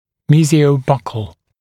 [ˌmiːzɪəu’bʌkl][ˌми:зиоу’бакл]мезиально-щечный